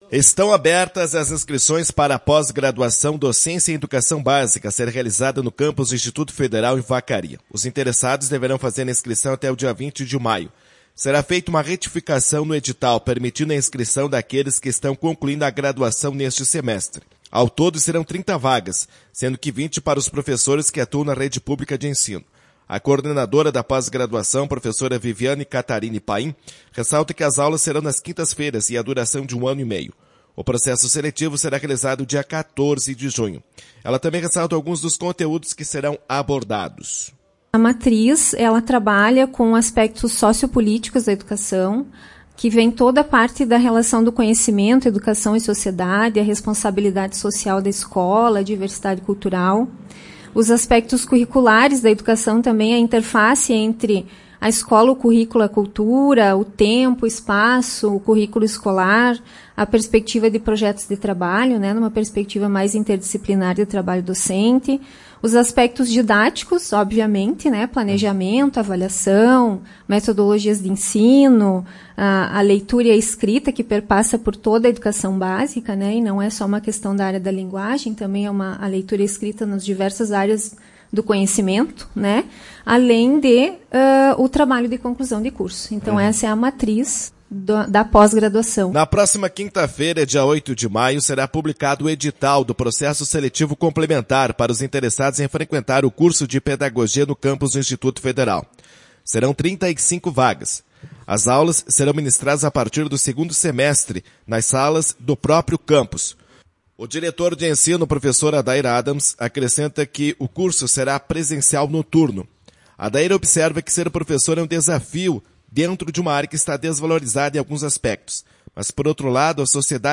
Professores estiveram no Temática